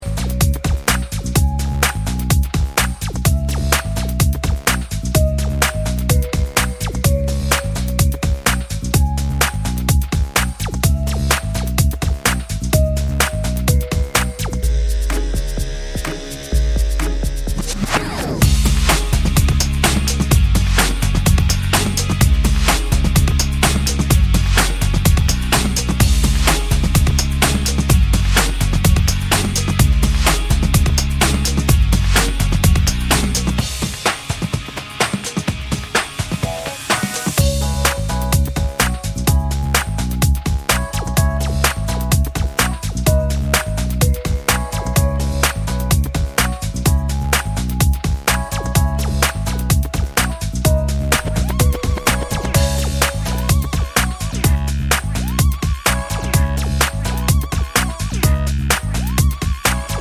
inc end ad-libs Pop (2000s) 3:55 Buy £1.50